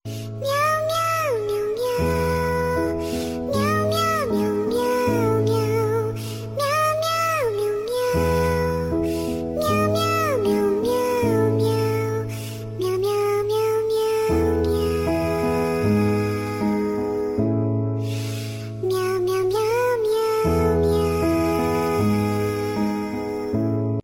miau.mp3